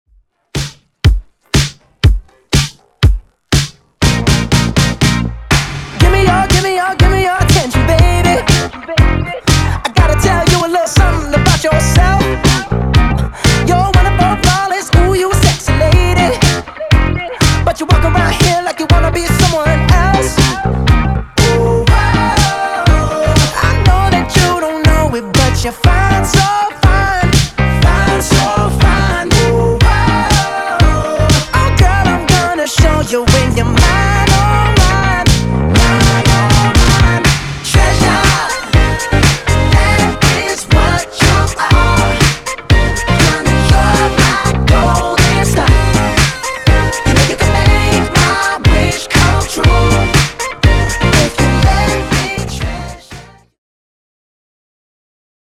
Genres: 2000's , RE-DRUM
Clean BPM: 86 Time